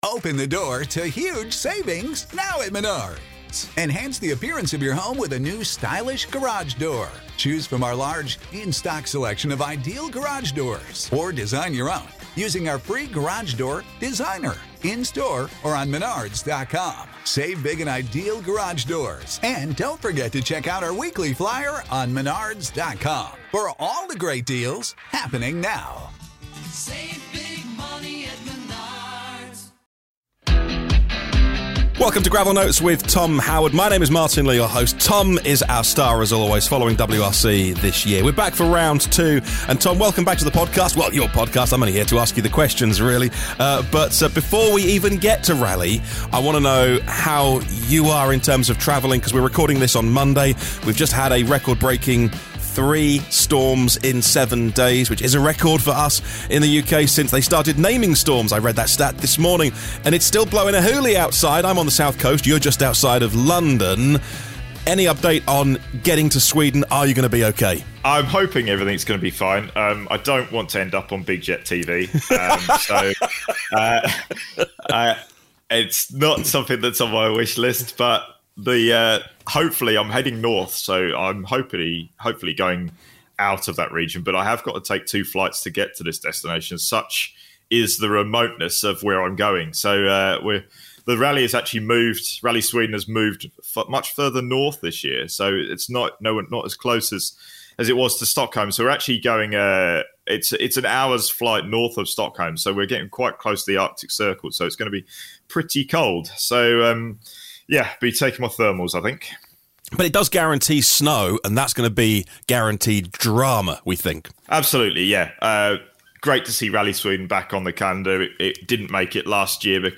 Following a 2021 break, Rally Sweden is back on the calendar and moving to a new location further north, which will guarantee a spectacular backdrop of snow and ice. On the podcast today we hear from Esapekka Lappi about his first start of the year and how the new cars will handle in the conditions.